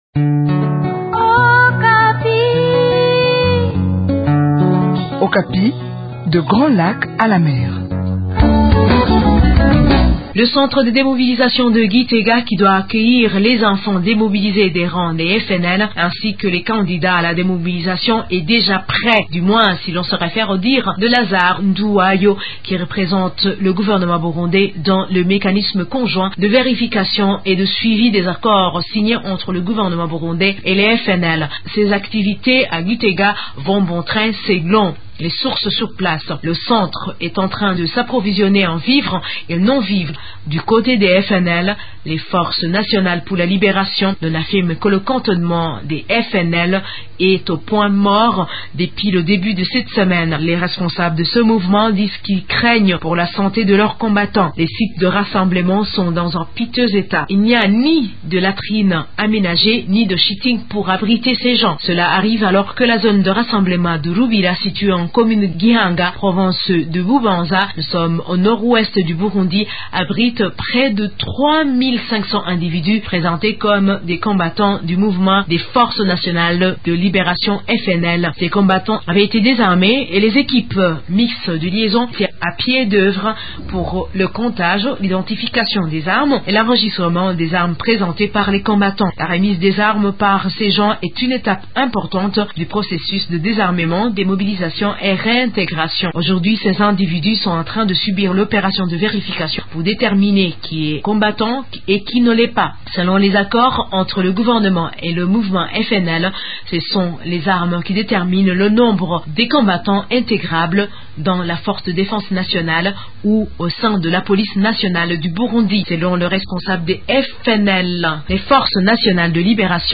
De Bujumbura, une correspondance